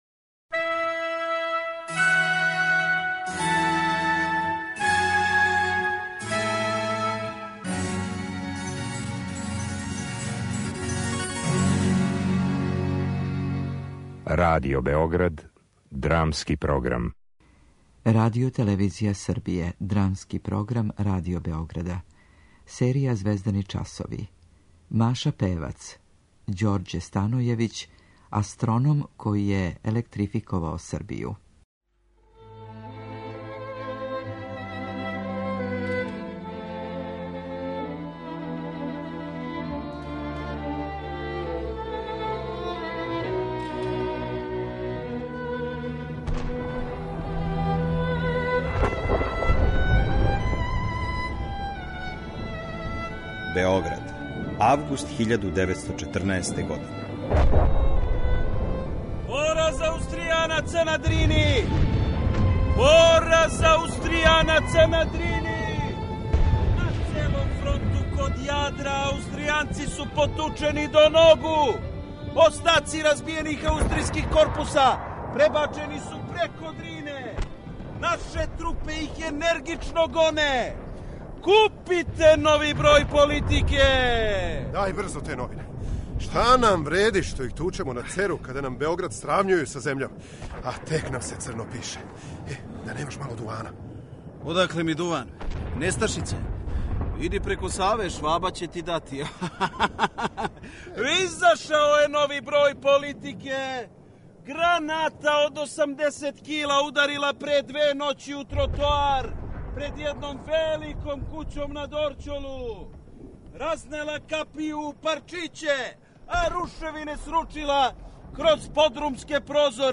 Драмски програм: Звездани часови